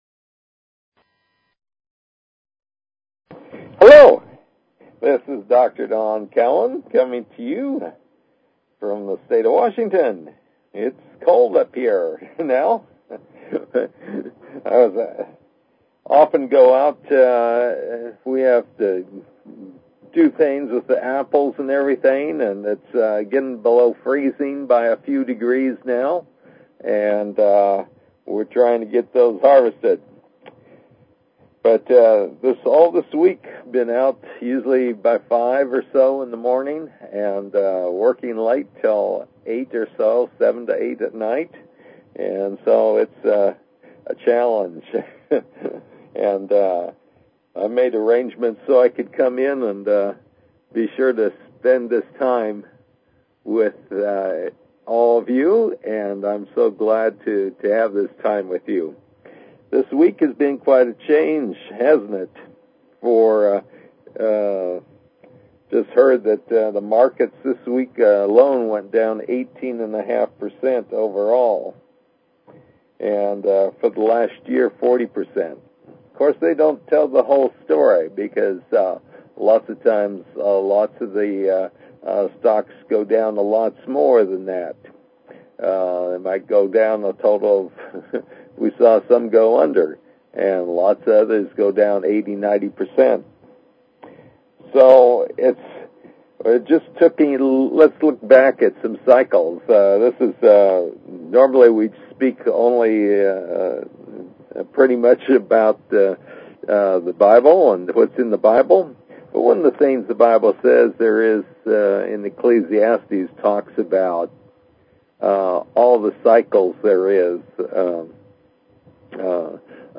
Talk Show Episode, Audio Podcast, New_Redeaming_Spirituality and Courtesy of BBS Radio on , show guests , about , categorized as